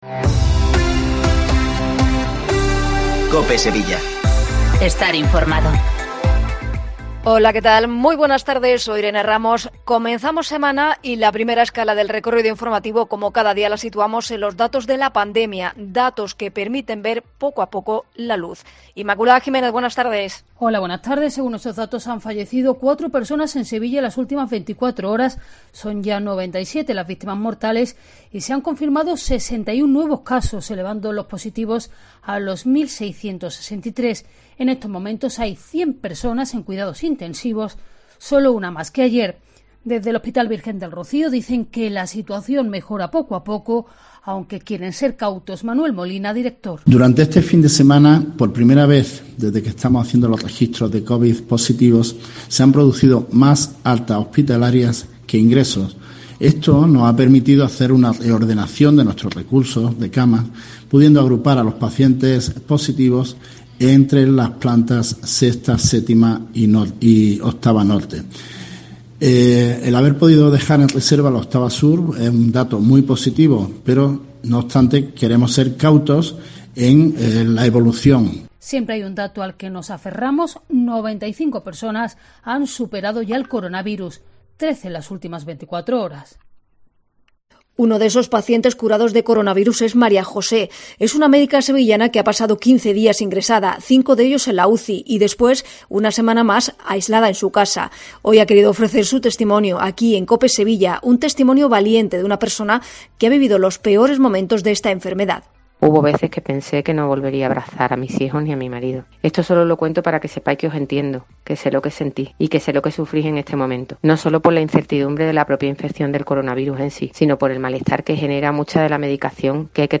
Toda la actualidad de Sevilla 14.20 informativos